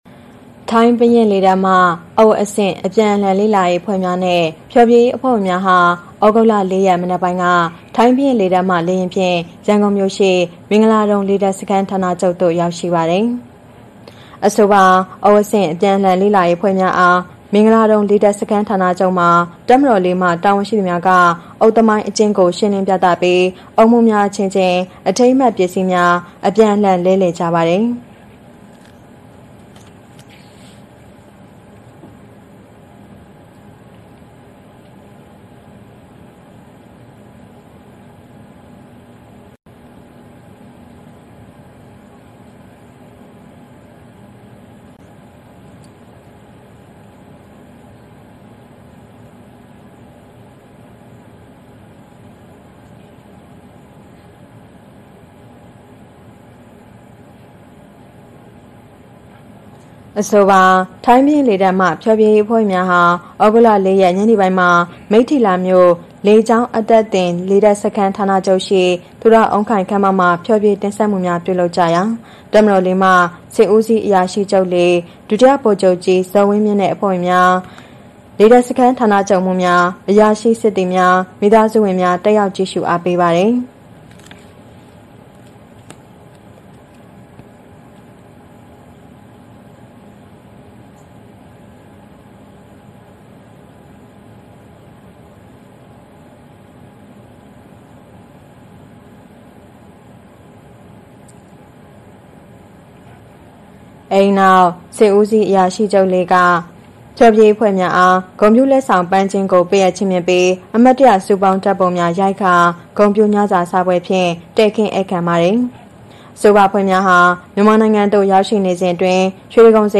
ထိုင်းဘုရင့်လေတပ်မှ ဖျော်ဖြေရေးအဖွဲ့များ မြန်မာနိုင်ငံသို့ လာရောက်ဖျော်ဖြေ
News